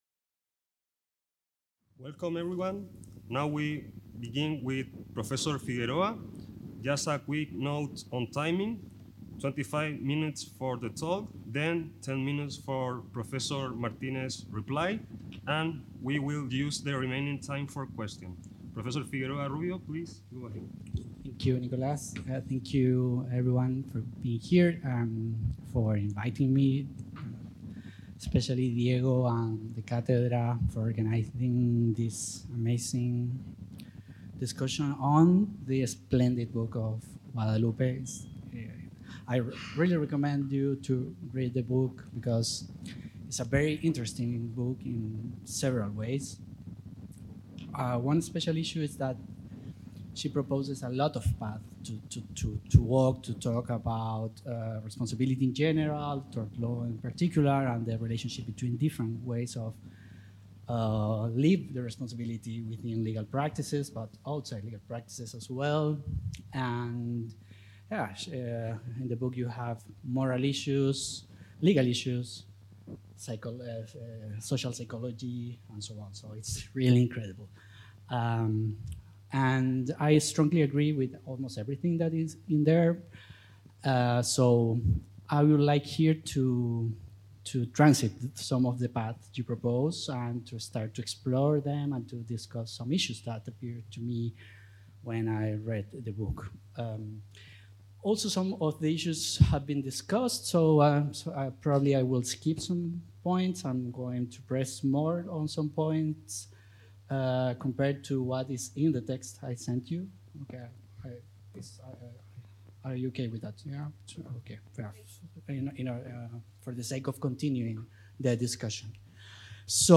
The Chair of Legal Culture is organizing the 9th Workshop on the Philosophy of Private Law